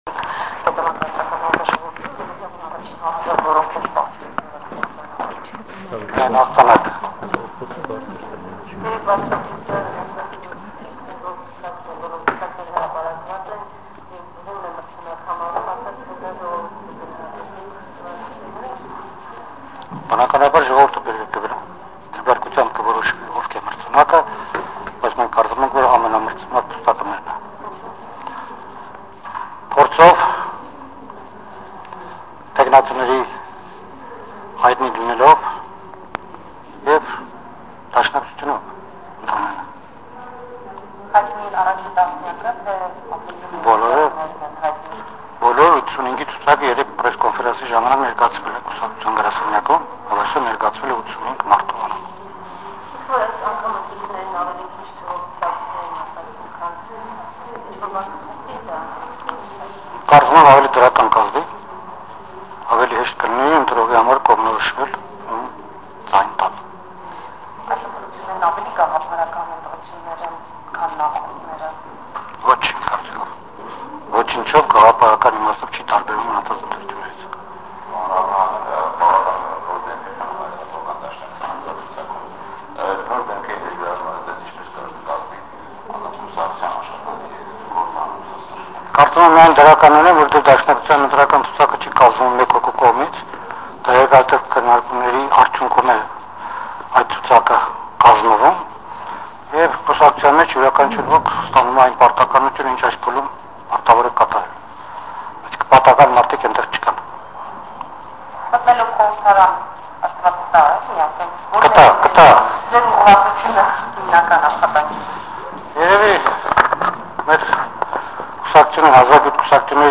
Լրագրողների հետ զրույցում նա ասաց, որ միայն հաղթանակ են ակնկալում: